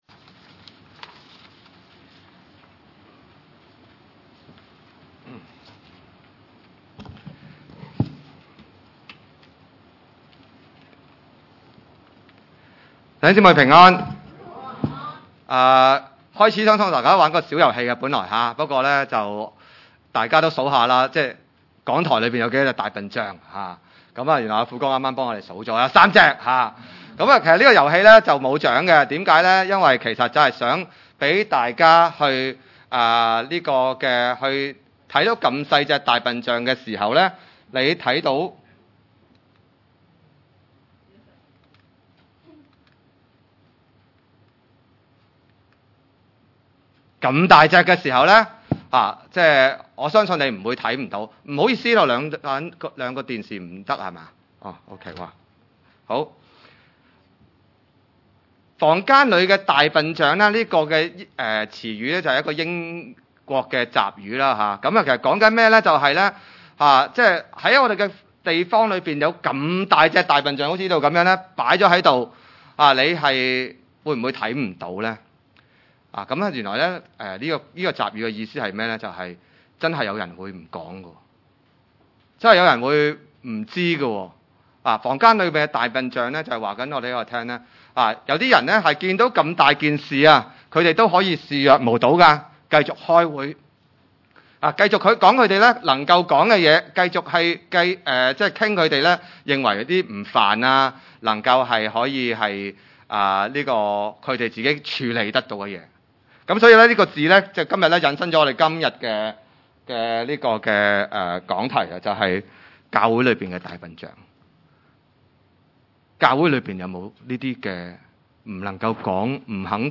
1-7 崇拜類別: 主日午堂崇拜 1.